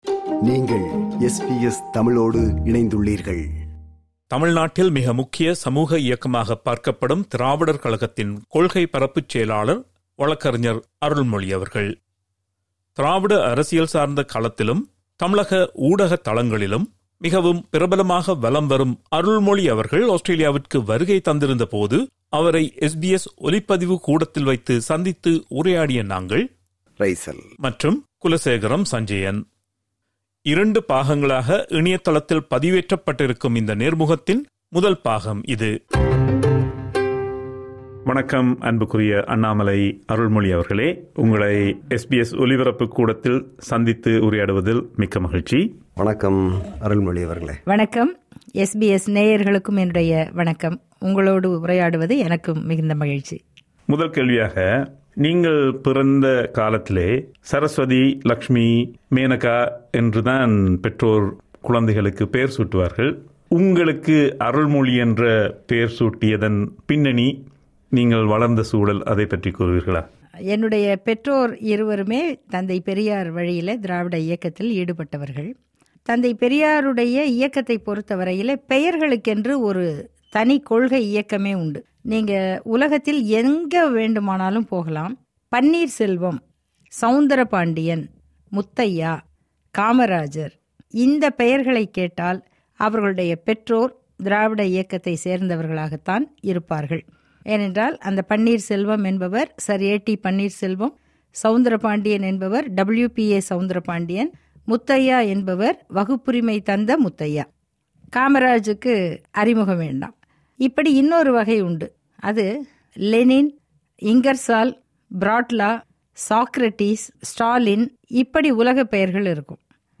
இரண்டு பாகங்களாக பதிவேற்றப்பட்டிருக்கும் நேர்முகத்தின் முதல் பாகம் இது.
SBS Studios